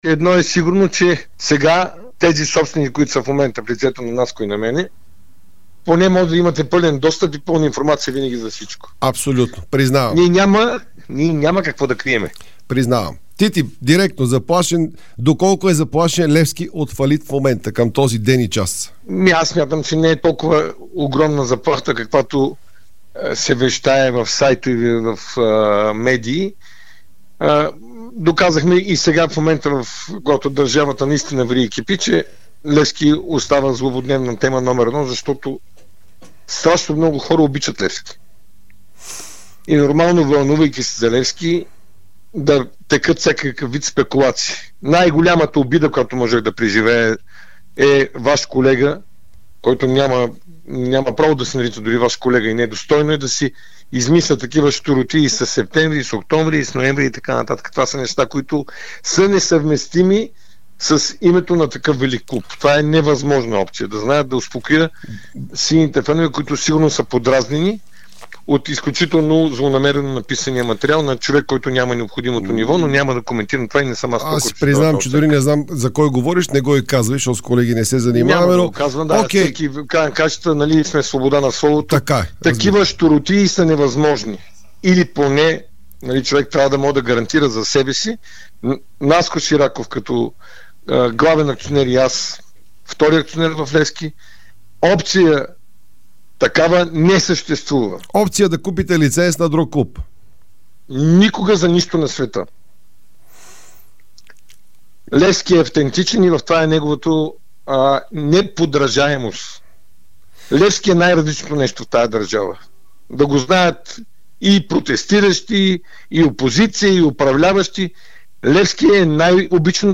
В специално интервю за Дарик радио и dsport той обяви, че ръководството на клуба възнамерява да въведе таван на заплатите.